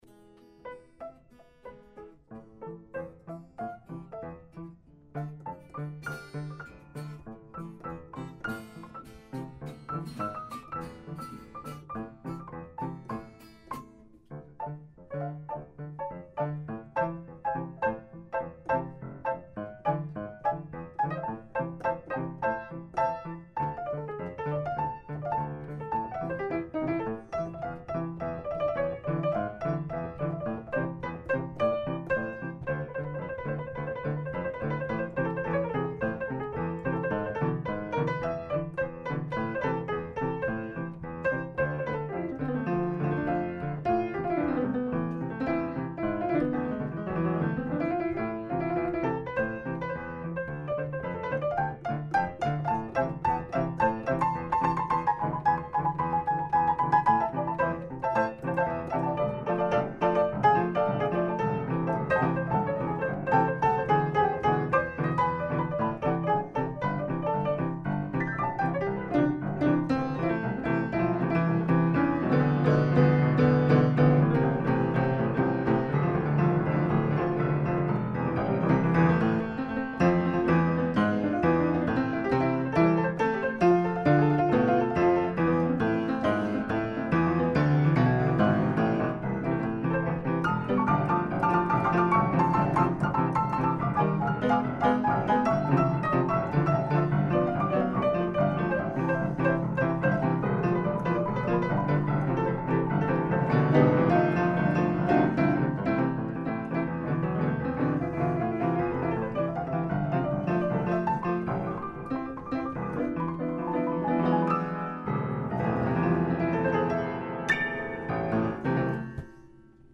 - Все, что вы услышите здесь, друзья, создано и исполнено одновременно.
На обычном языке это именуется импровизацией.
Почти все пьесы сыграны на пианино, на старом моем Беккере.
Прошу вашего снисхождения к качеству домашней аудиозаписи и техническим погрешностям моего исполнения, которые я намеренно не правлю аудиоредактурой, чтобы сохранить живой, непосредственный характер сего действа.